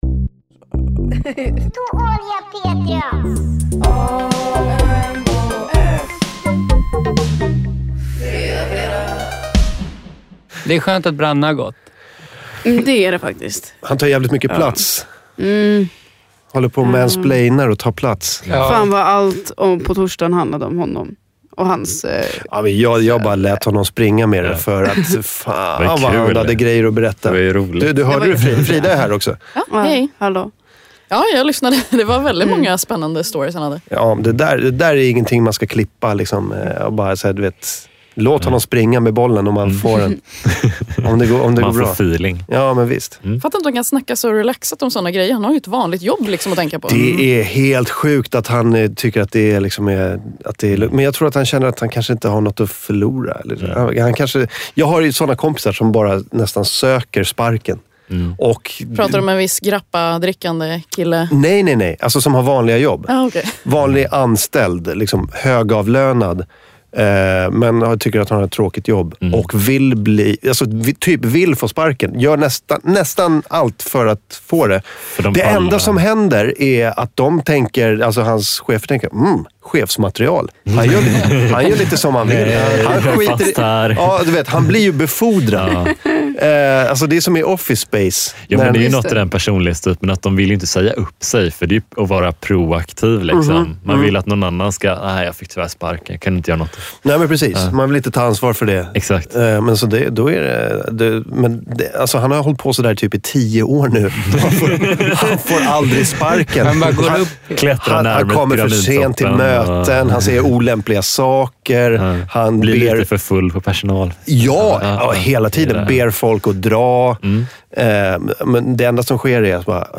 Downloads Download AMK_Fredag_s01e22.mp3 Download AMK_Fredag_s01e22.mp3 Content Idag är vi hela fem stycken i studion